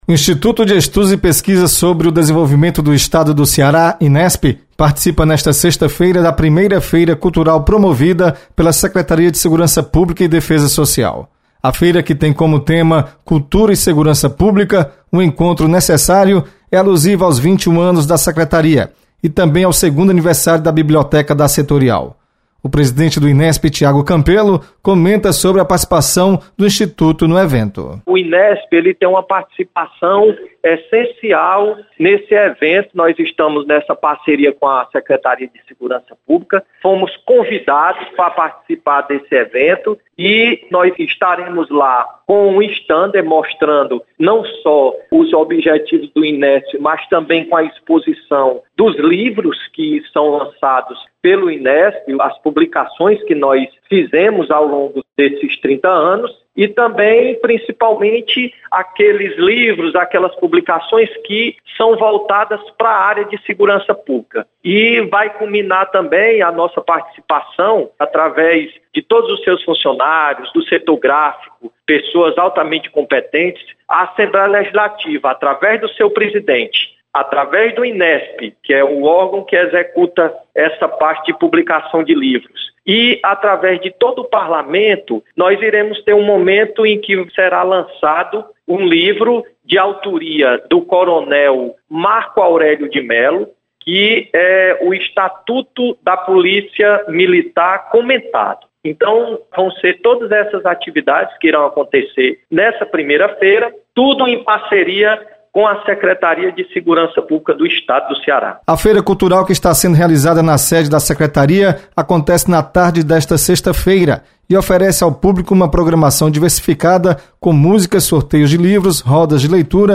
Você está aqui: Início Comunicação Rádio FM Assembleia Notícias Inesp